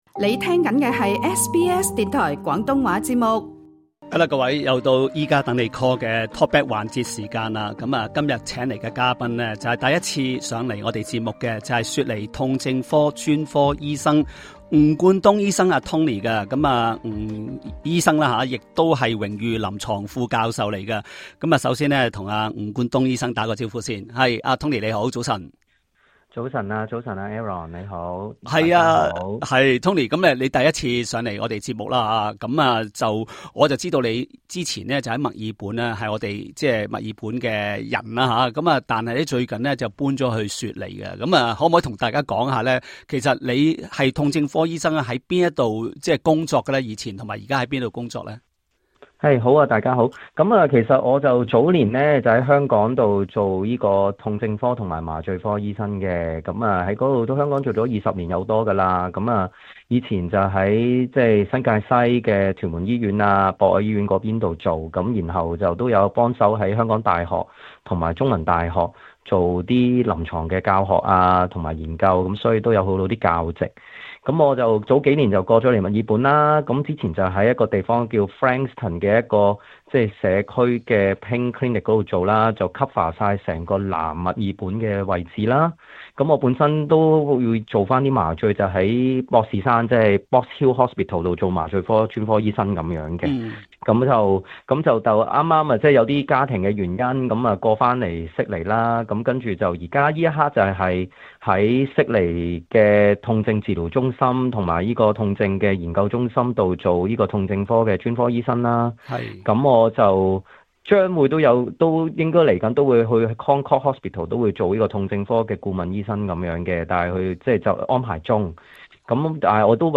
talkback 環節